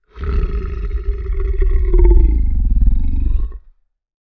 creature-sound